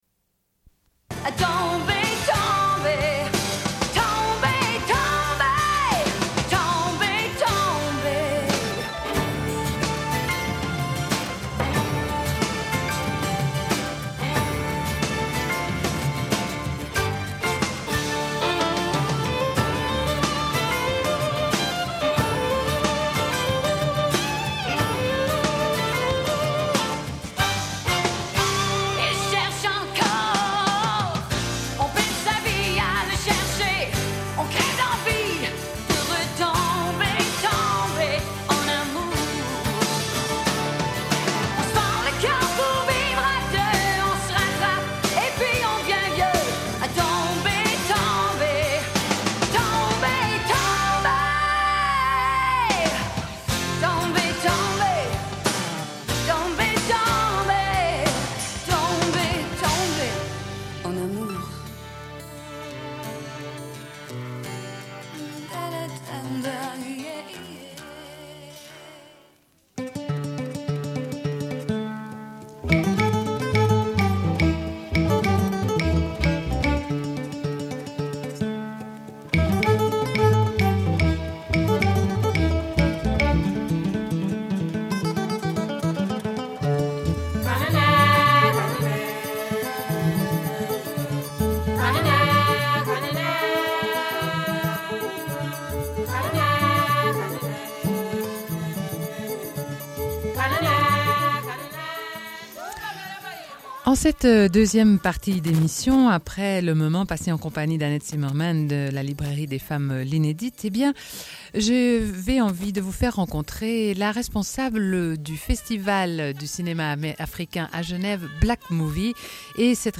Une cassette audio, face A31:32